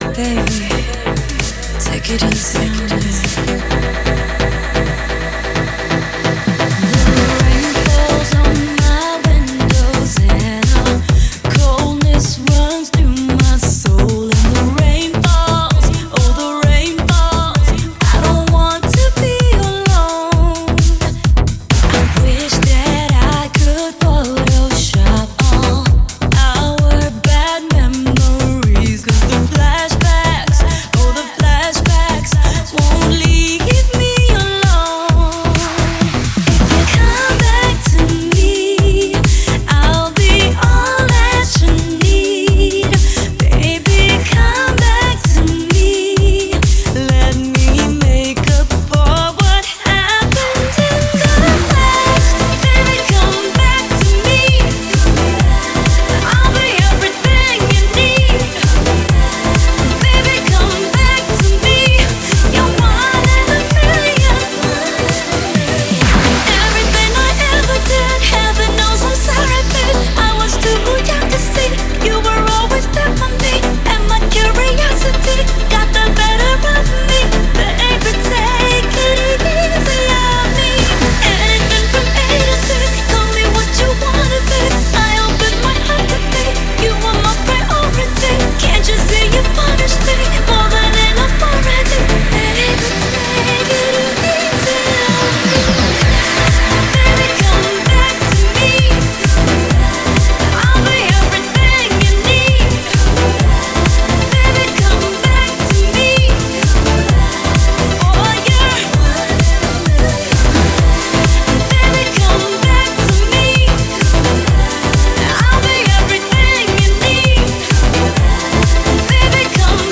BPM130
Audio QualityPerfect (High Quality)
dance track